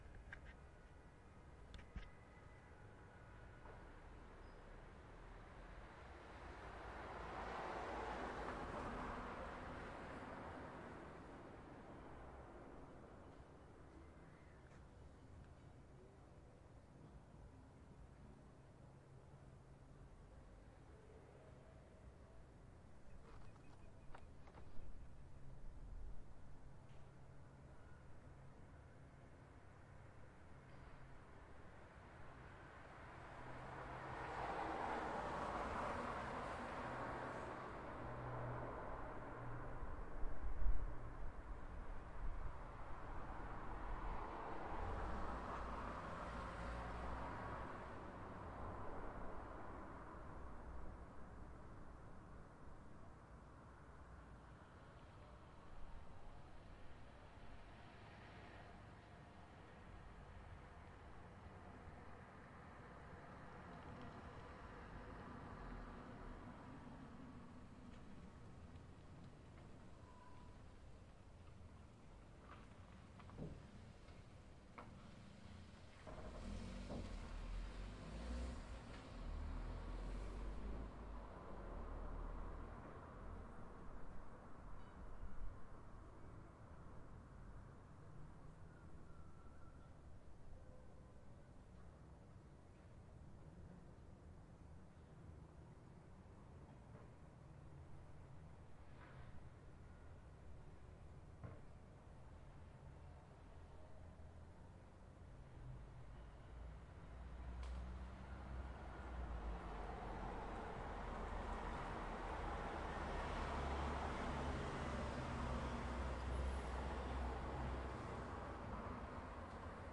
雨中的繁忙街道 - 声音 - 淘声网 - 免费音效素材资源|视频游戏配乐下载
在一条繁忙的城市街道上的雨天在Northridge，加州。在繁忙的十字路口（Balboa Blvd＆amp; Parthenia Street）的早高峰时段用Roland R05录制。汽车在潮湿的道路上行驶的哗哗声，各种各样的车辆。